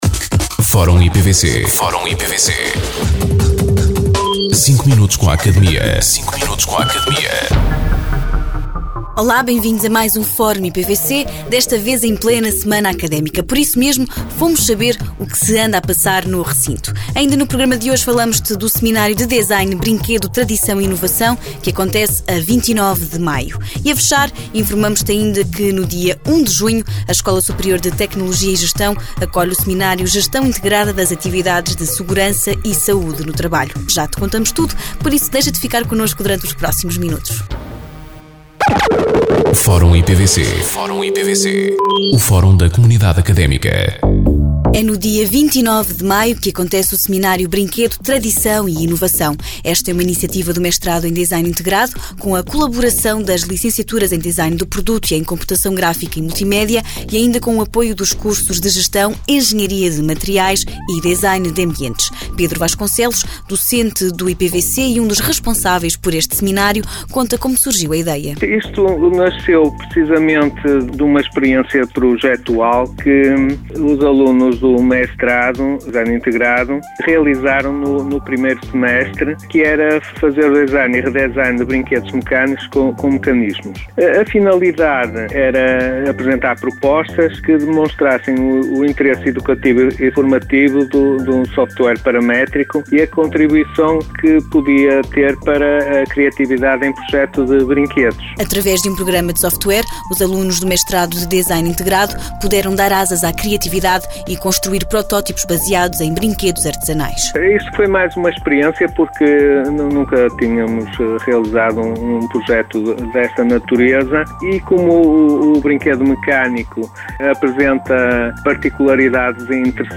Entrevistados:
Vários Estudantes IPVC